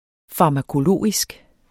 Udtale [ fɑmakoˈloˀisg ]